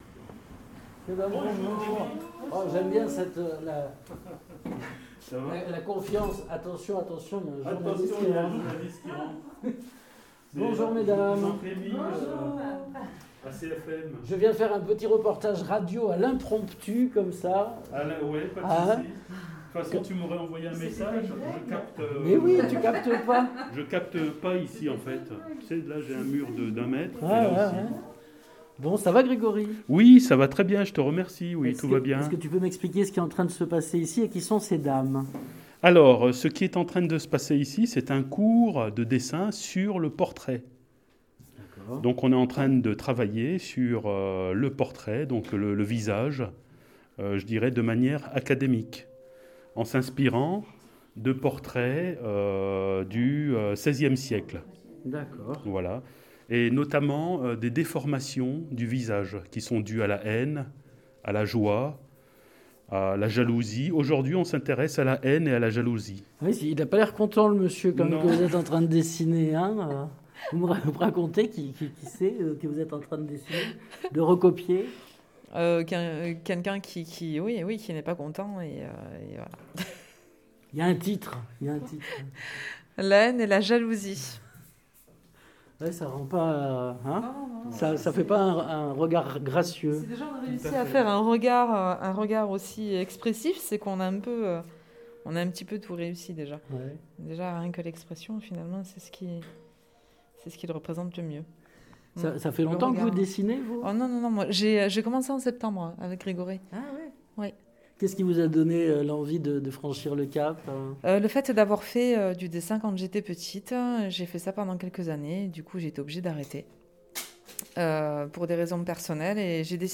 Fondée en 2013, L’Académie Ingres à Montauban(82) est une école de dessin et de peinture agrée par l’Education Nationale. Reportage lors d’un atelier sur le portrait.
Interviews